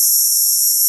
rr3-assets/files/.depot/audio/sfx/forced_induction/spool_01.wav
spool_01.wav